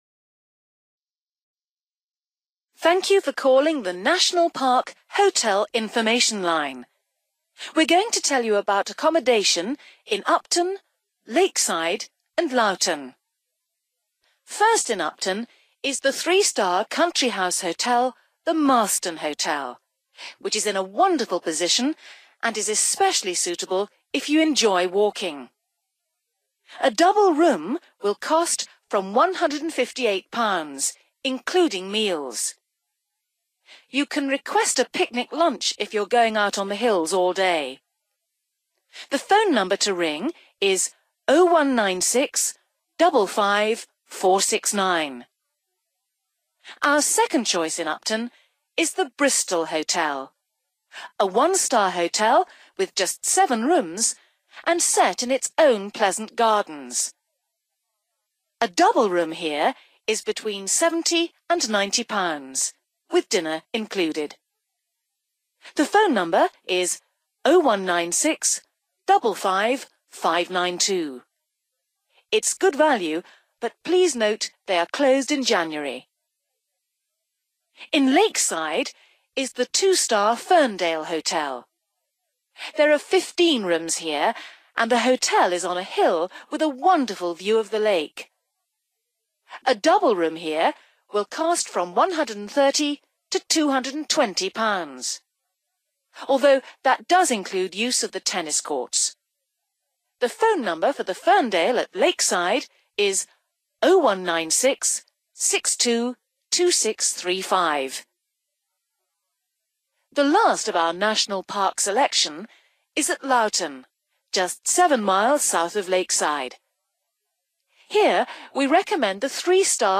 You will hear a recorded message about hotels in the National Park.